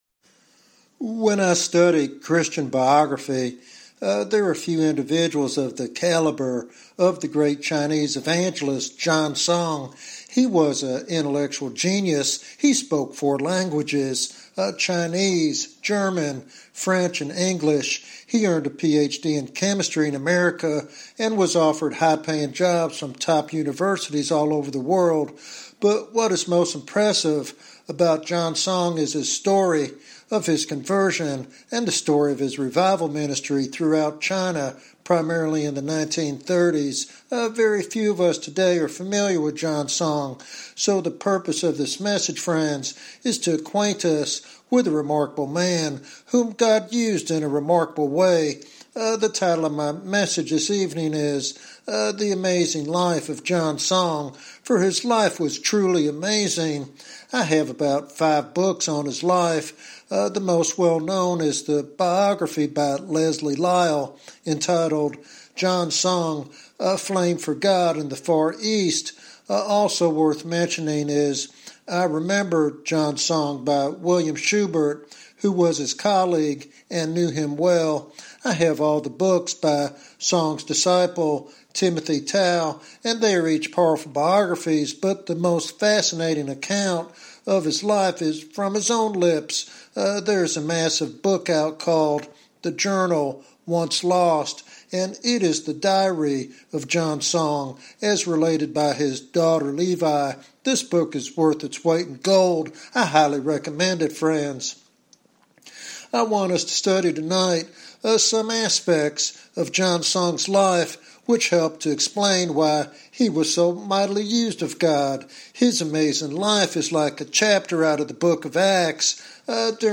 In this biographical sermon